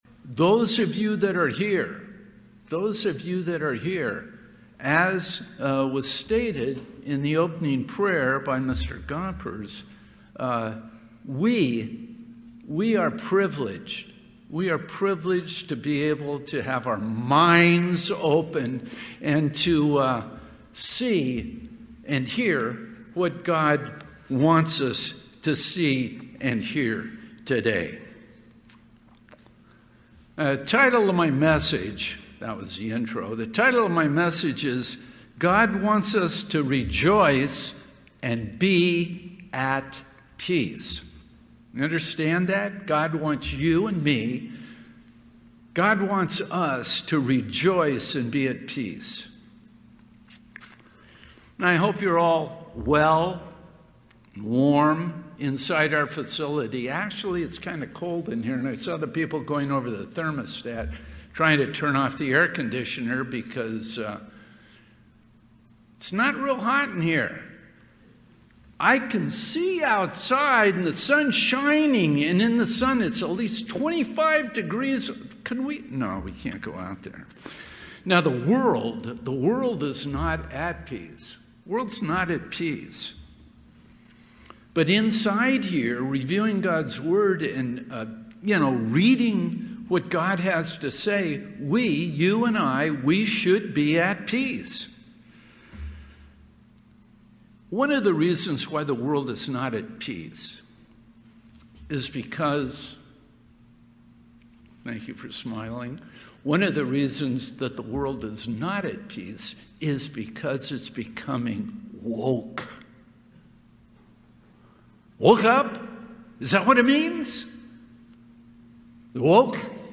Sermons
Given in Las Vegas, NV Redlands, CA San Diego, CA